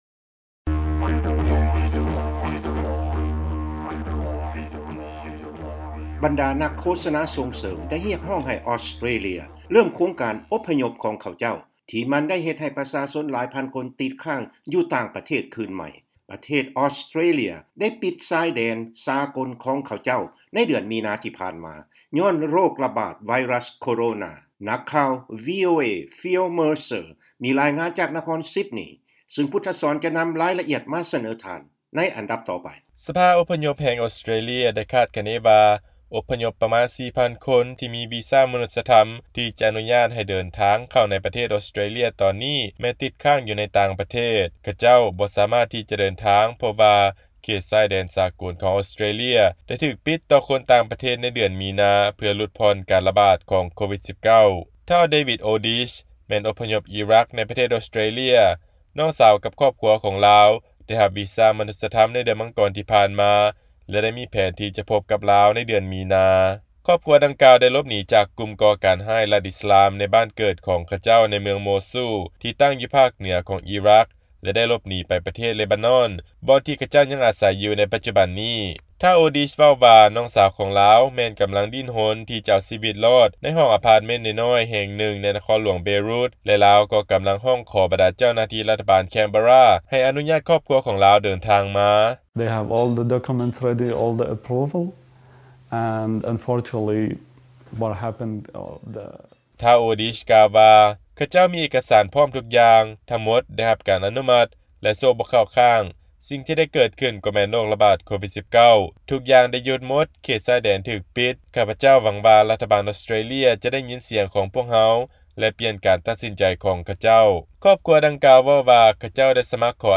ຟັງລາຍງານ ອົບພະຍົບທີ່ຕິດຄ້າງ ຢູ່ຕ່າງປະເທດ ຮຽກຮ້ອງໃຫ້ ອອສເຕຣເລຍ ຜ່ອນຜັນ ມາດຕະການ ປິດເຂດຊາຍແດນຍ້ອນ COVI-19